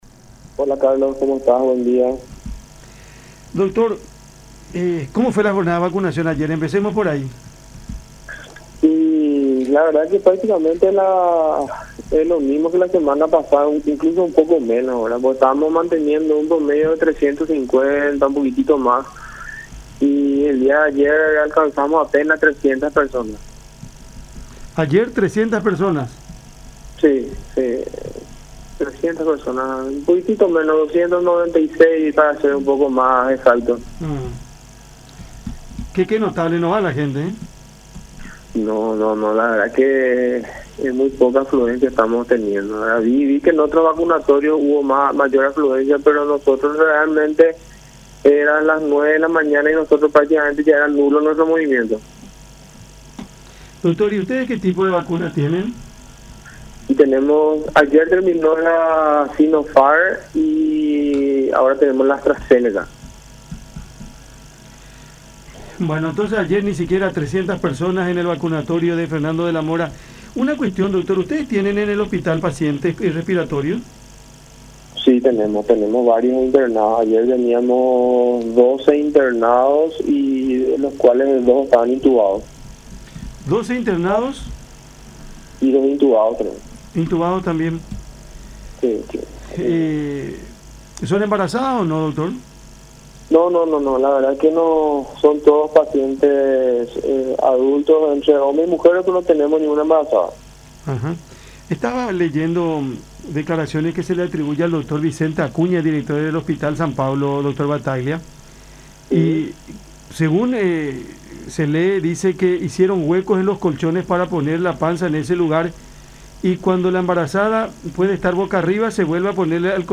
en conversación con Cada Mañana a través de La Unión